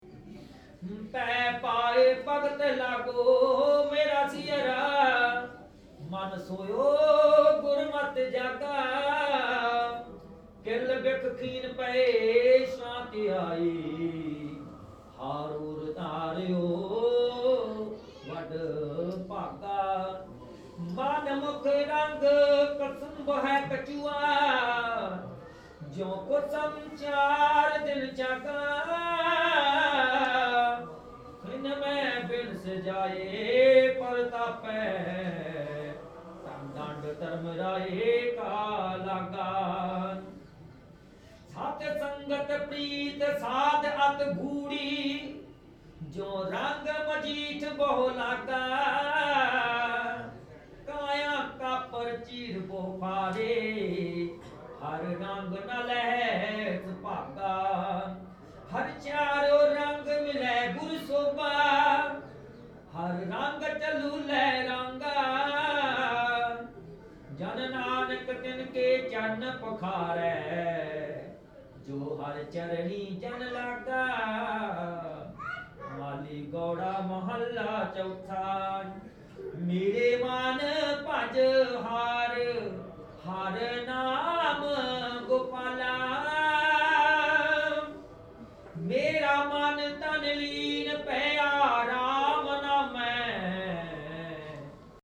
L'intérieur du temple grouille de vie : à chaque étage des prêtres chantent, des enfants courent, des gens lisent, des musiciens jouent, des femmes nettoient vitres et plafonds.